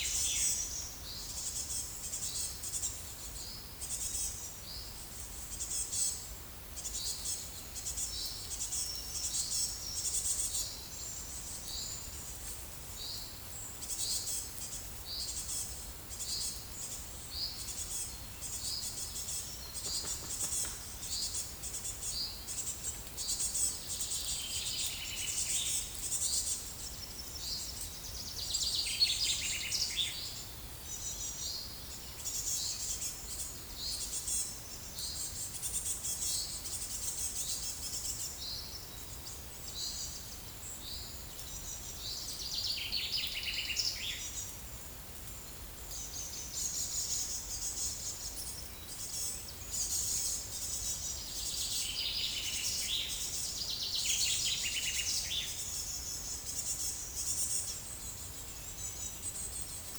Cyanistes caeruleus
Periparus ater
Parus major
Fringilla coelebs
Regulus ignicapilla
Certhia brachydactyla